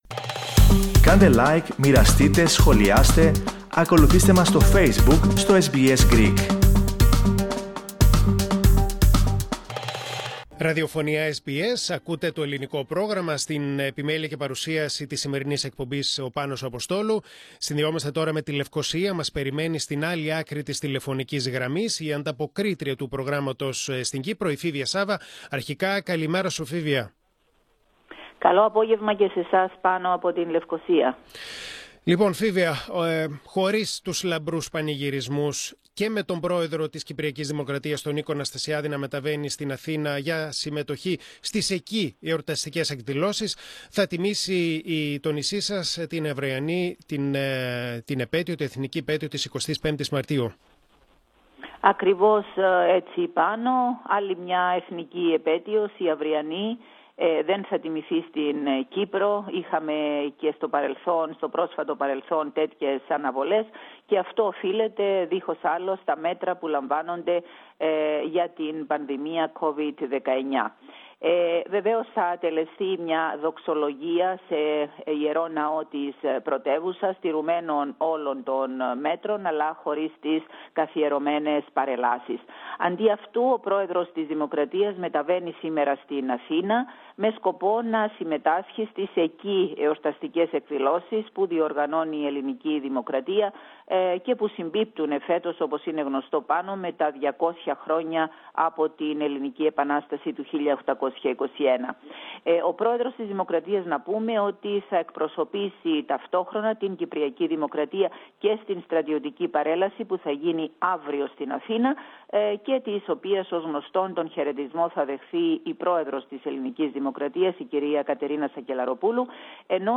Στην τέταρτη χαμηλότερη θέση στην ΕΕ σε σχέση με τα ποσοστά γονιμότητας (1,33 γεννήσεις ανά γυναίκα) βρίσκεται η Κύπρος. Ακούστε την ανταπόκριση